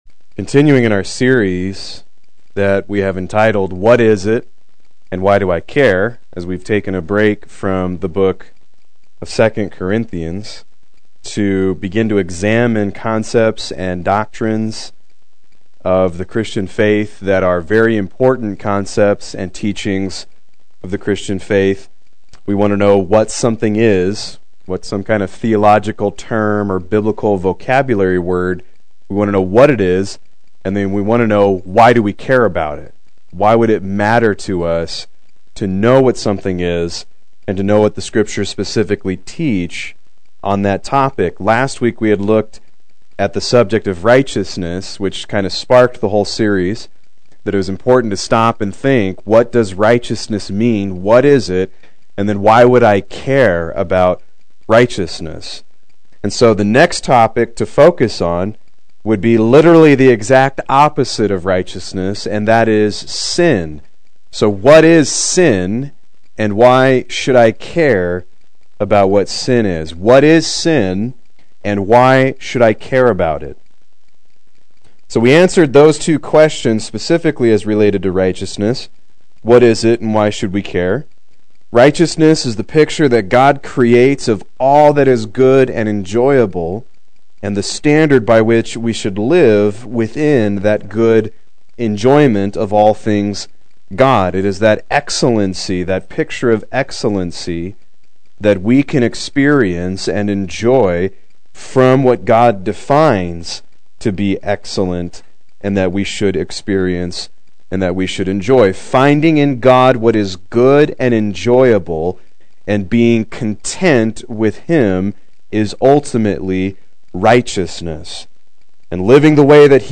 Proclaim Youth Ministry - 02/25/17
Play Sermon Get HCF Teaching Automatically.